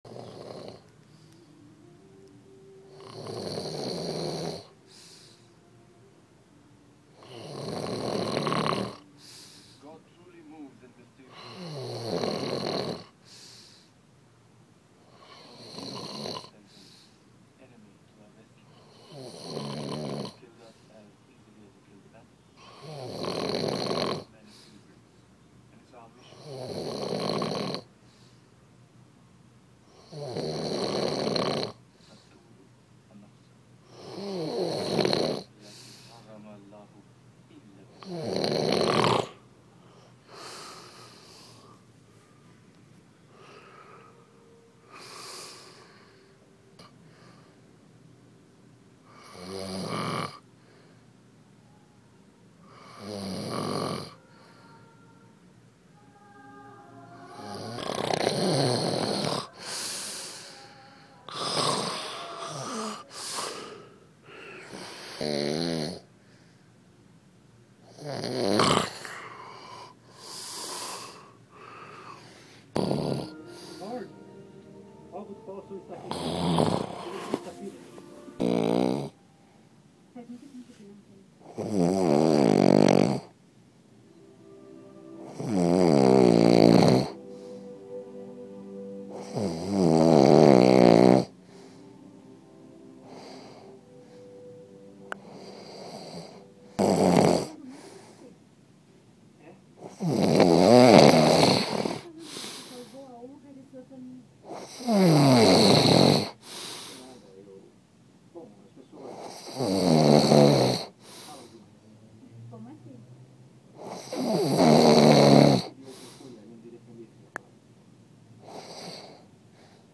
O-Ronco.m4a